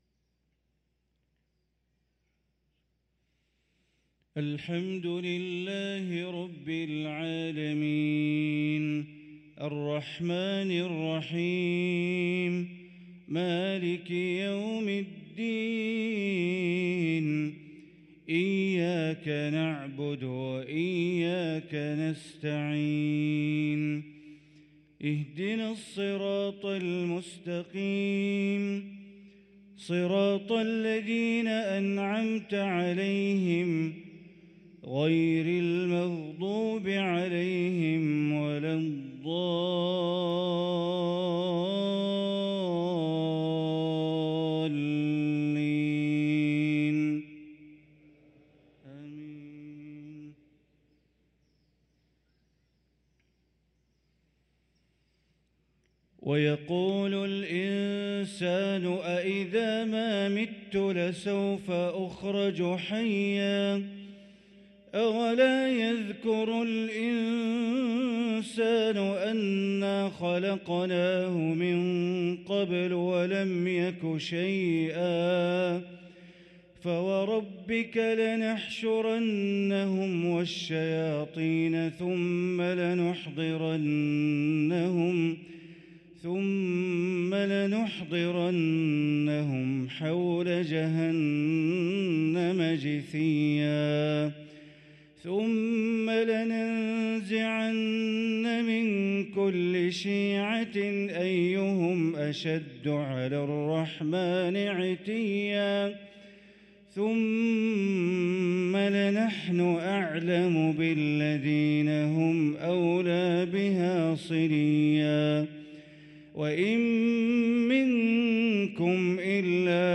صلاة الفجر للقارئ بندر بليلة 22 ربيع الأول 1445 هـ
تِلَاوَات الْحَرَمَيْن .